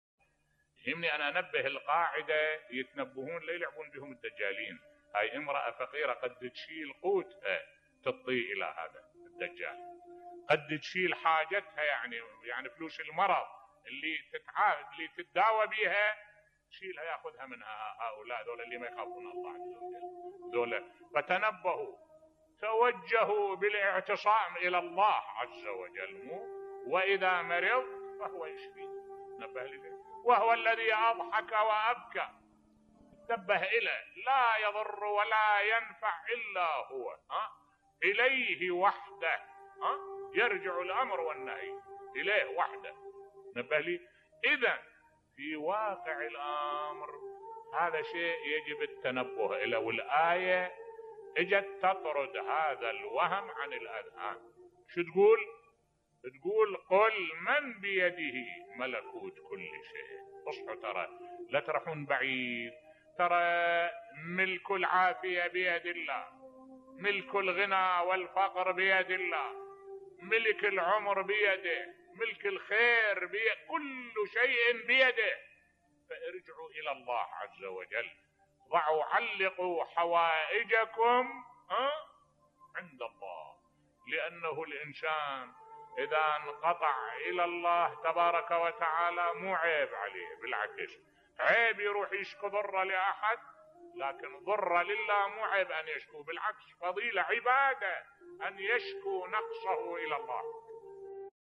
ملف صوتی إعتصموا بحبل الله في قضاء حوائجكم بصوت الشيخ الدكتور أحمد الوائلي